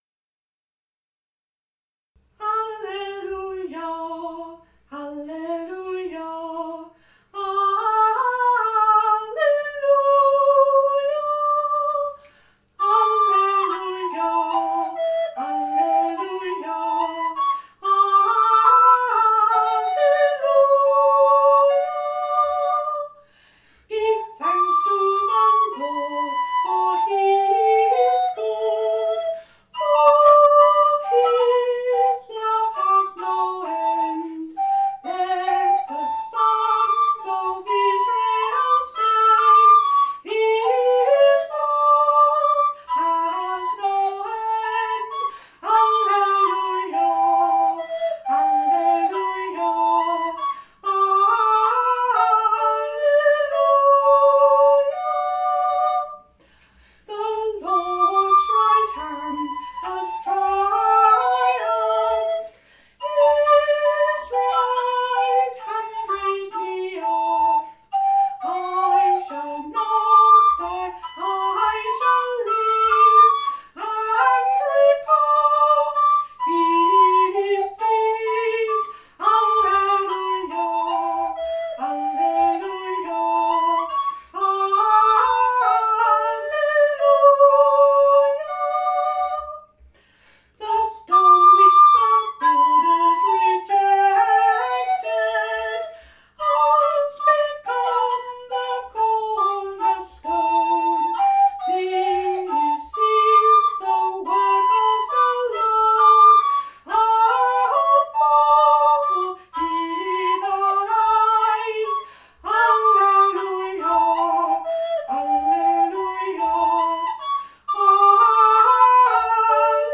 playing the recorder